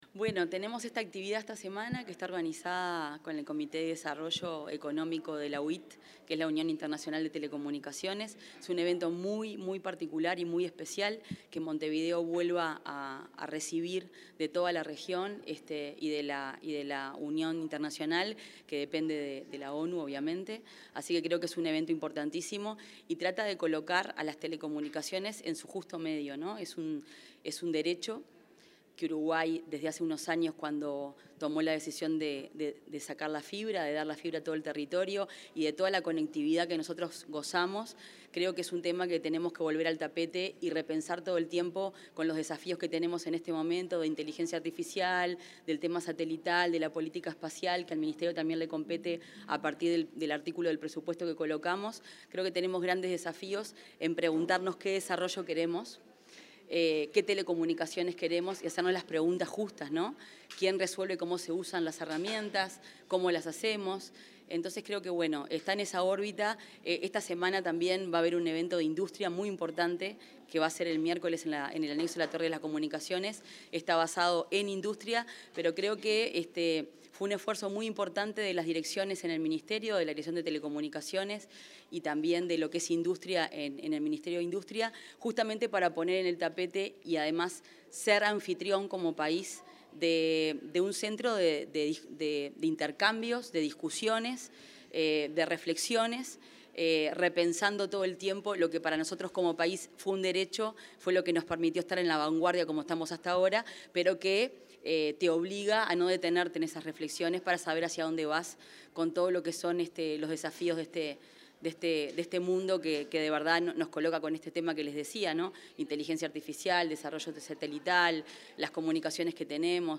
Declaraciones de la ministra de Industria, Fernanda Cardona
La ministra de Industria, Energía y Minería, Fernanda Cardona, diálogo con los medios de prensa tras la apertura del Coloquio de Política y Economía